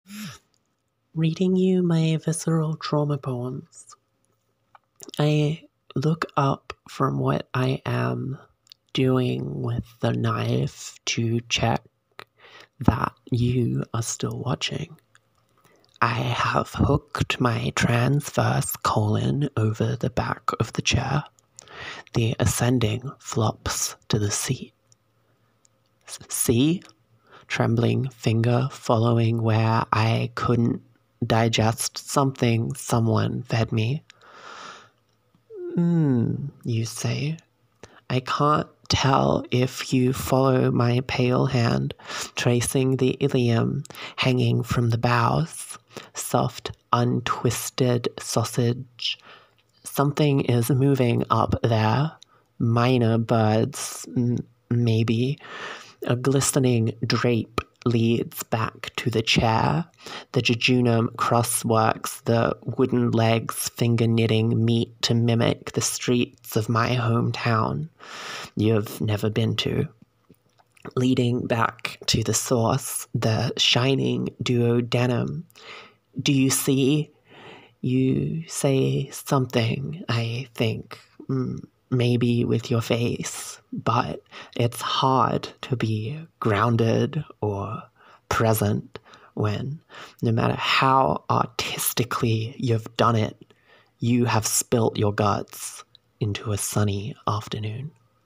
Reading you my visceral trauma poems